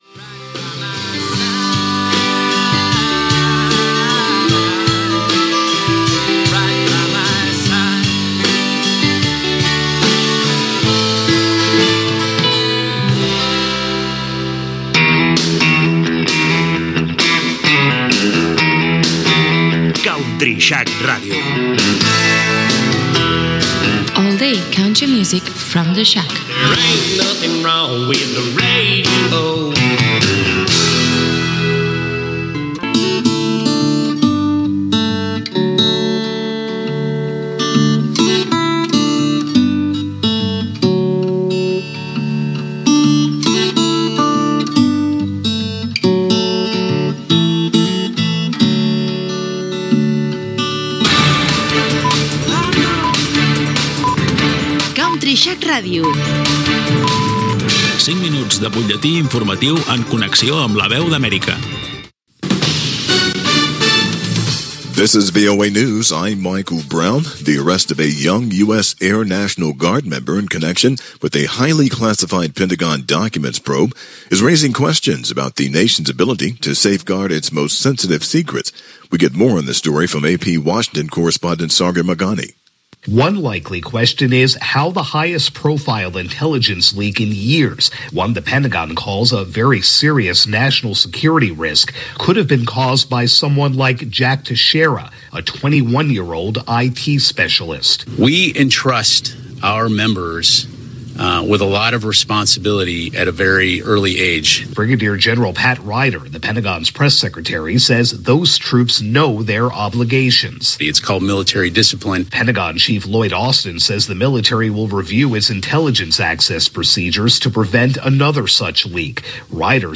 Indicatiu de l'emissora, fragment musical, identificació, butlletí de notícies de Voice of America: accés a documents secrets, visita del president d'EE.UU. Joe Biden a Irlanda, guerra d'Ucraïna, immigrants d'Amèrica llatina, Sudan. Indicatiu i tema musical.
Informatiu